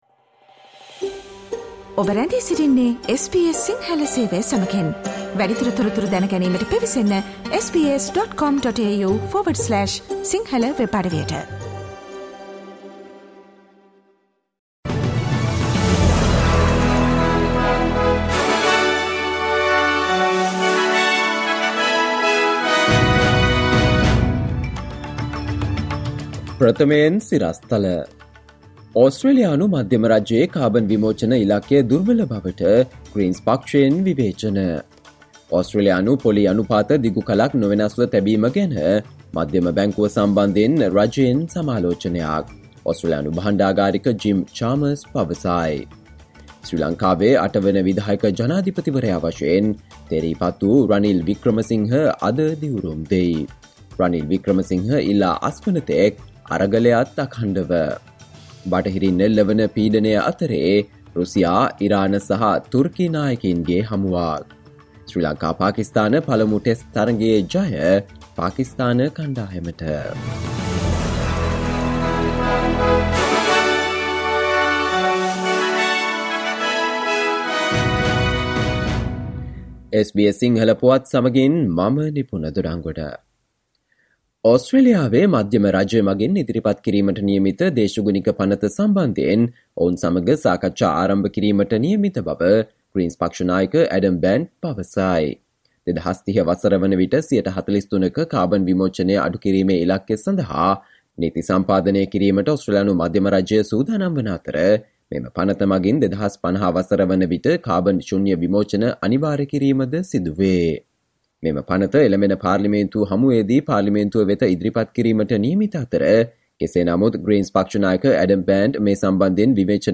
සවන්දෙන්න 2022 ජූලි 21 වන බ්‍රහස්පතින්දා SBS සිංහල ගුවන්විදුලියේ ප්‍රවෘත්ති ප්‍රකාශයට...